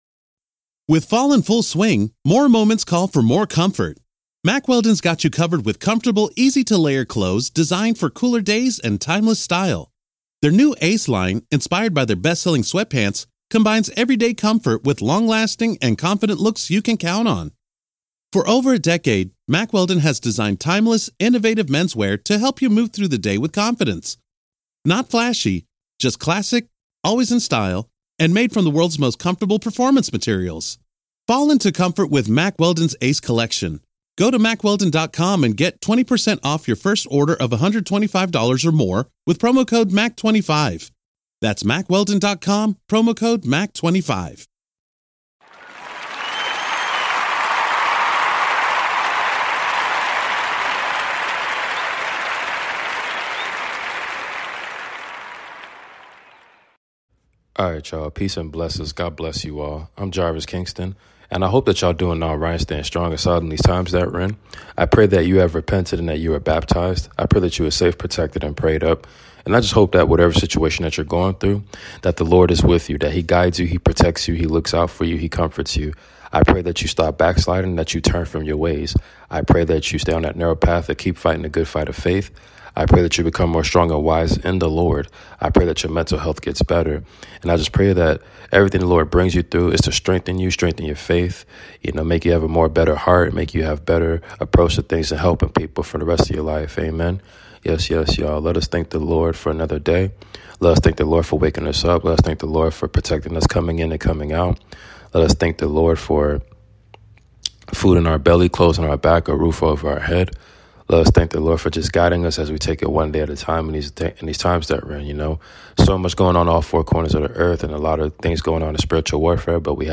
Book of Acts chapters 1-7 reading! Ask The Lord For The Holy Spirit!